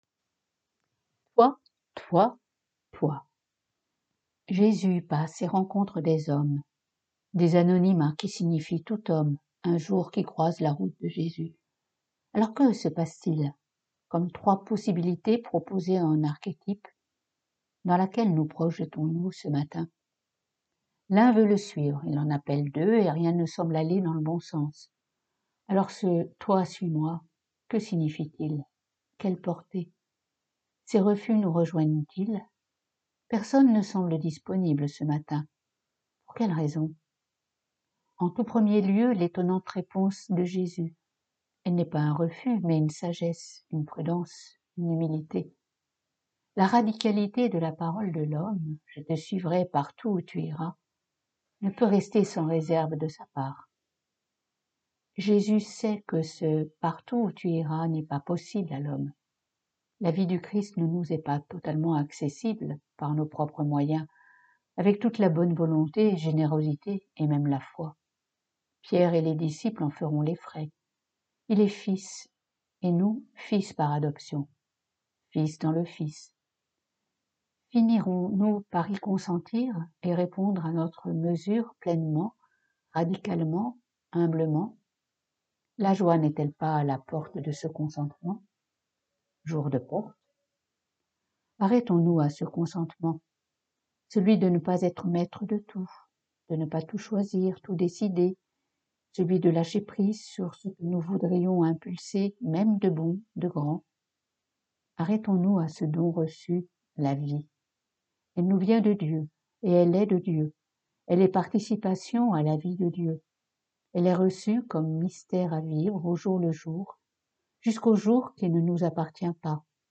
pour chaque billet mentionné ci-dessous, un lien vers le fichier-son enregistré au monastère, suivi d’un lien vers le billet en texte (mise à jour fréquente)